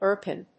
イアコン